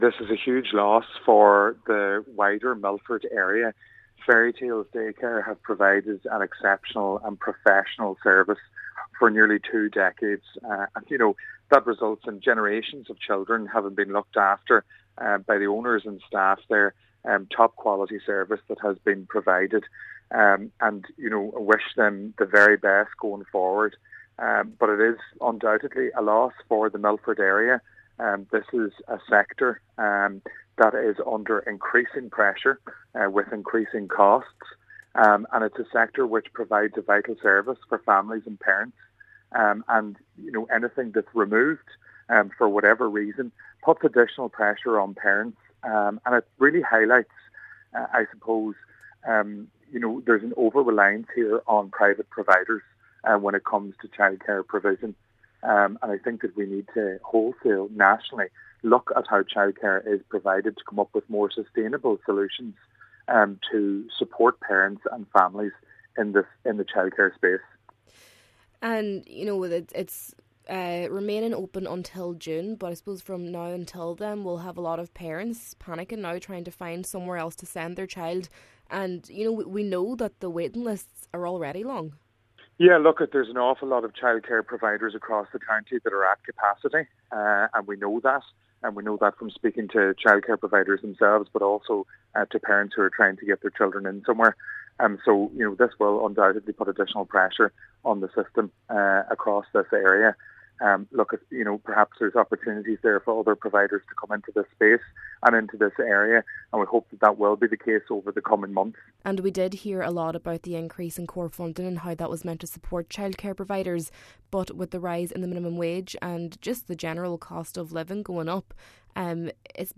Local Cllr Declan Meehan paid tribute to the staff and said more support needs to be given to the early years sector: